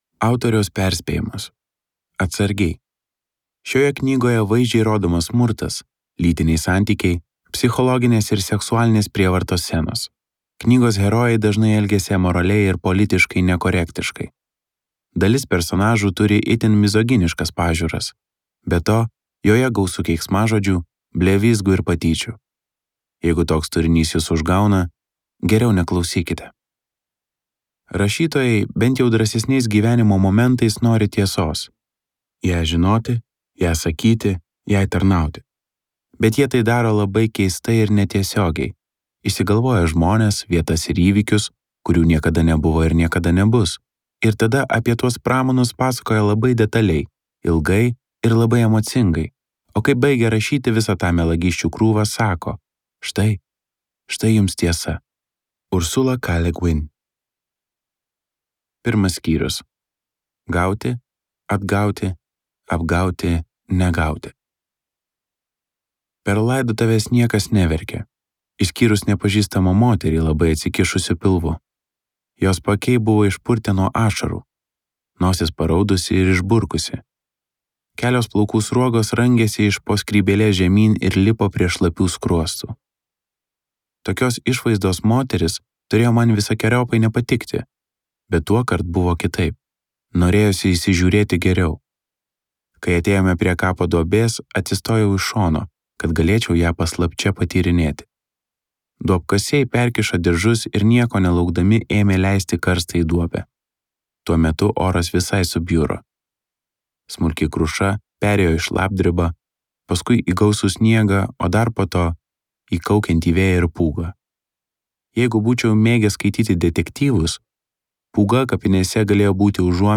Audioknygos leidybą iš dalies finansavo Lietuvos kultūros taryba.